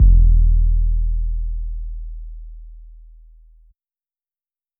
808s
JJCustom808s.wav